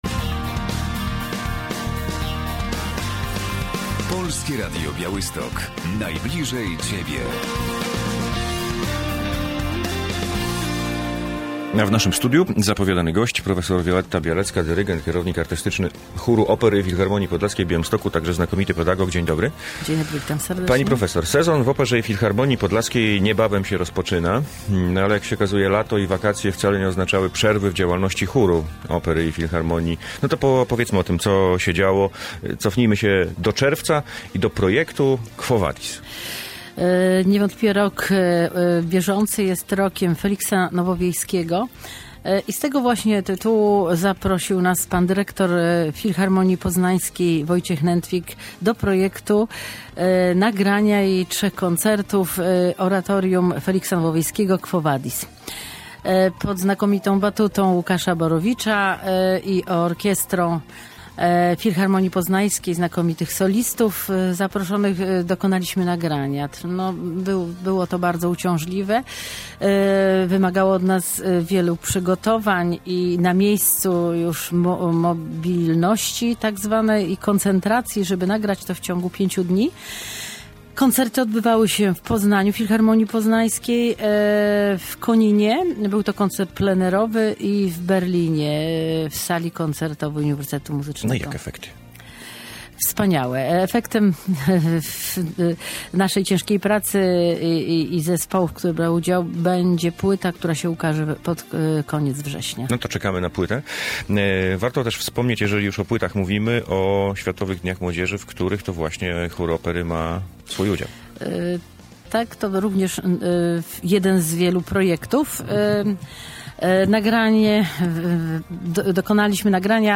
Studio Radia Bialystok